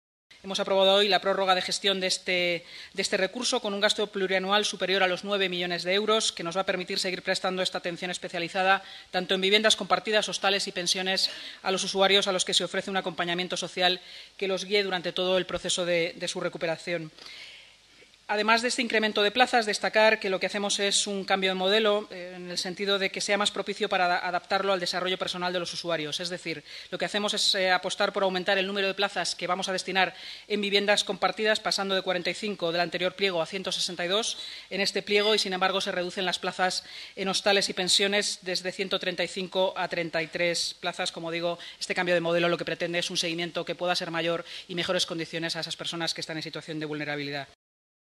Nueva ventana:Declaraciones de la portavoz municipal, Inmaculada Sanz